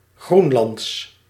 Ääntäminen
IPA: /ɡʁɔ.ɛn.lɑ̃.dɛ/